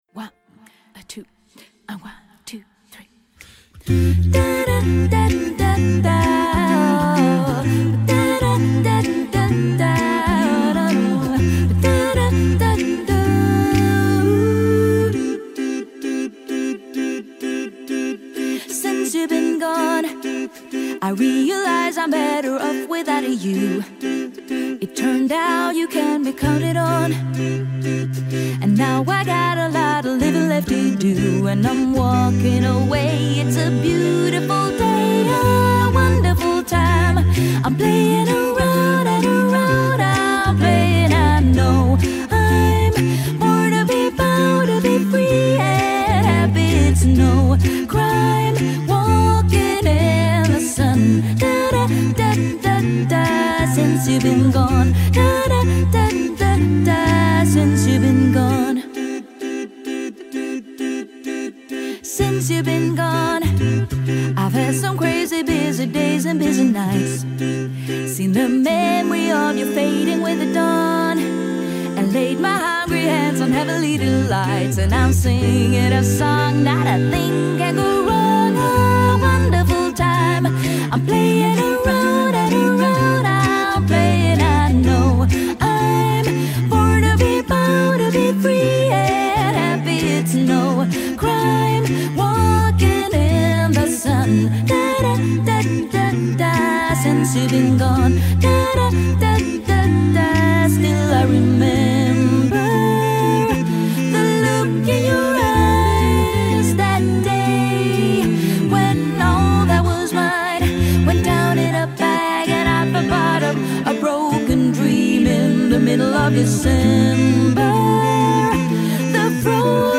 Voicing: SATBB a cappella